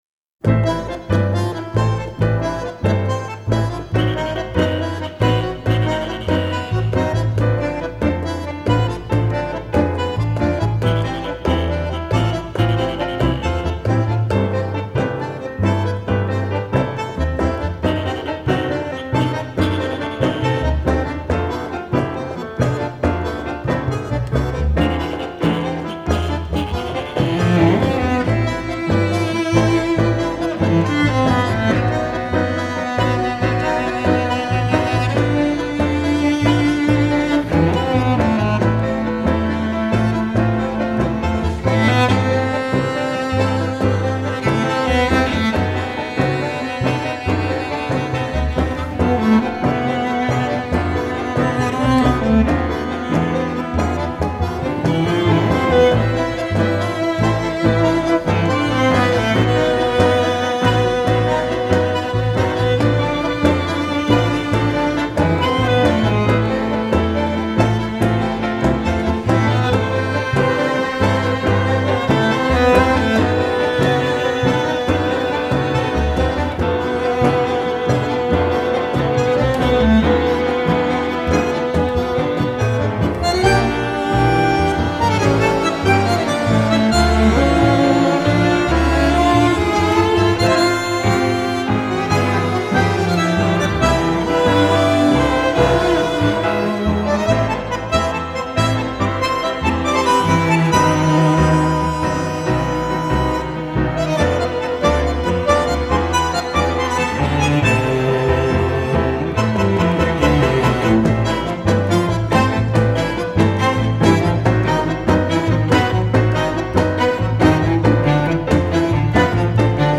Tango Music | Argentinian Band
The 5pc ensemble includes:
Acoustic Guitar, Violin, Bandoneon, Acoustic Bass and Piano